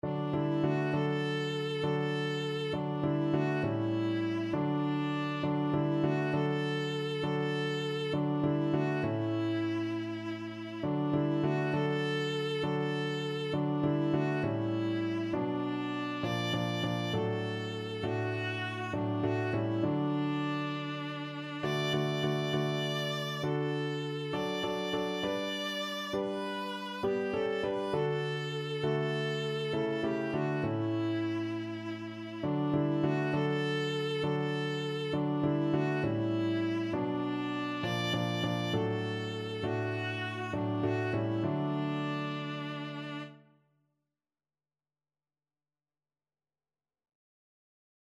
Viola
9/8 (View more 9/8 Music)
D major (Sounding Pitch) (View more D major Music for Viola )
Classical (View more Classical Viola Music)